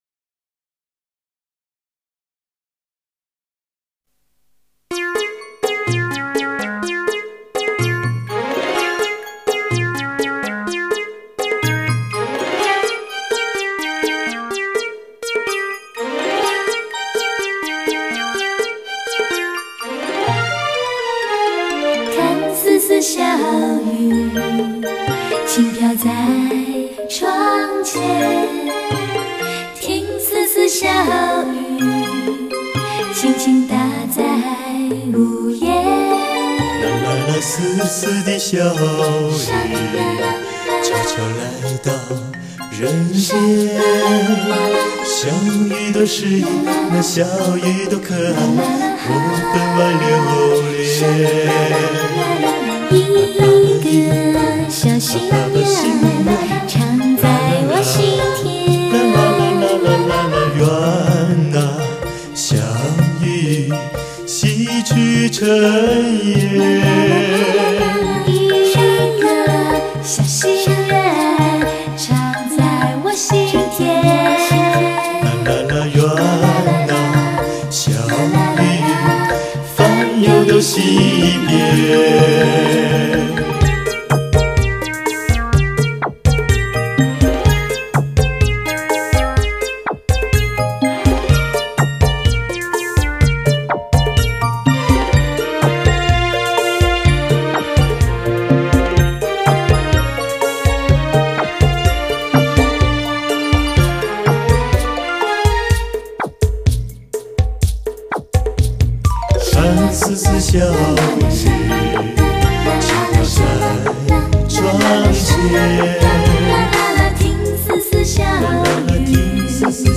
錄音機：TASCAMA-80 24軌糢擬錄音機 MIC:U-87 非常廣泛使用的一種電容麥尅風